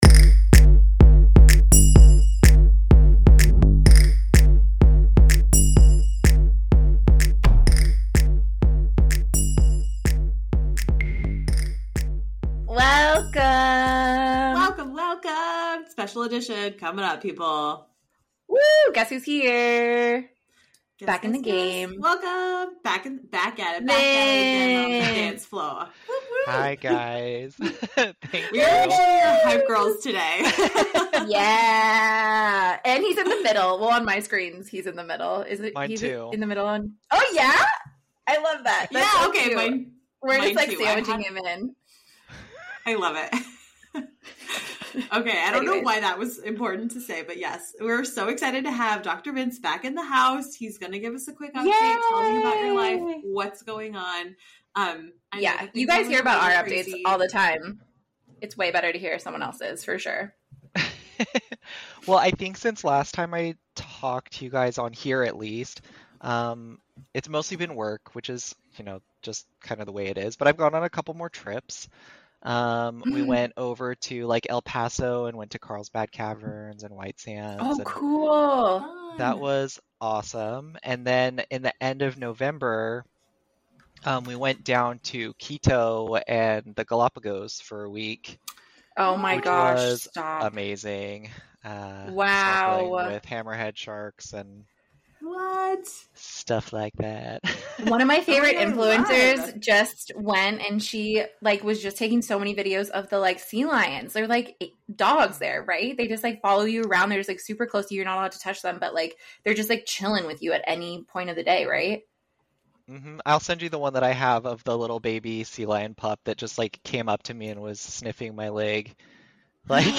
The three discuss the Fourth Wing series and the upcoming third book!